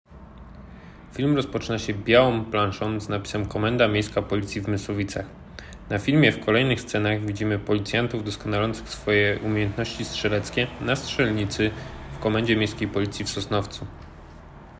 Opis nagrania: audiodeskrypcja do filmu